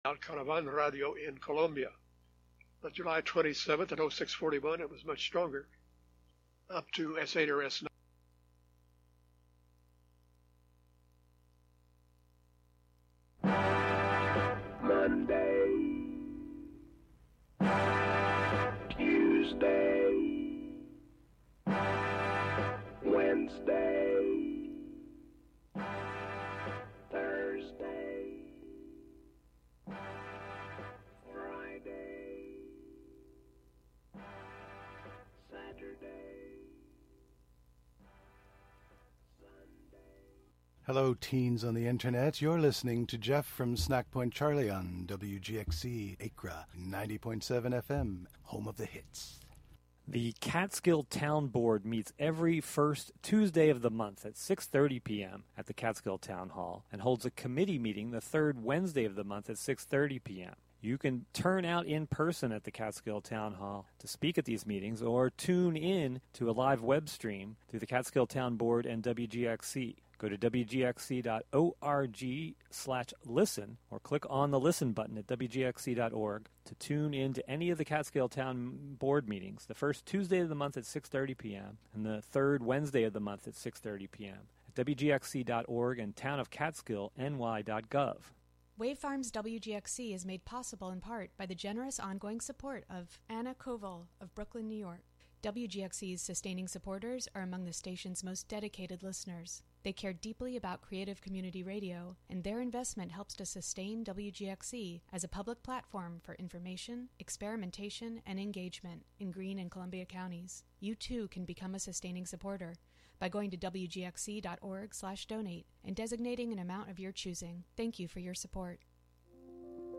A radio series of proprioceptive exercises, interviews about practices of communication, and archival sound. A routine for warming up our means of communication. Presented monthly as a combination of live and prerecorded sessions.